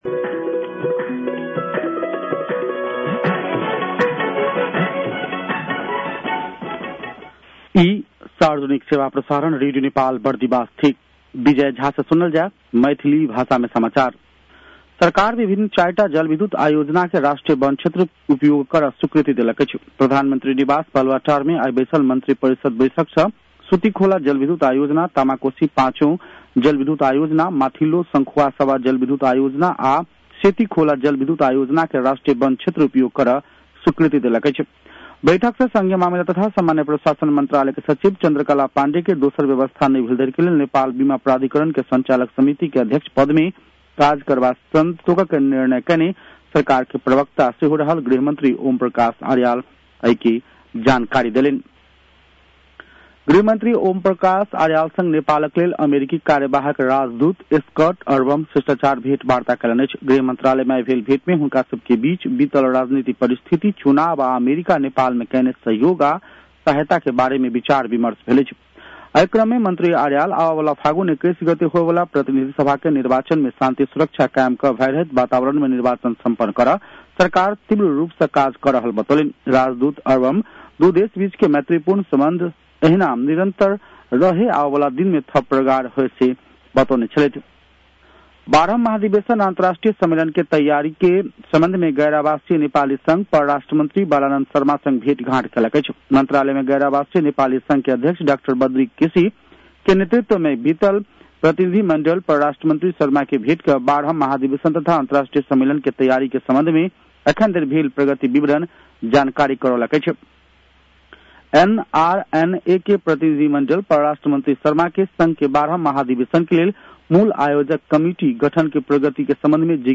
An online outlet of Nepal's national radio broadcaster
मैथिली भाषामा समाचार : १२ माघ , २०८२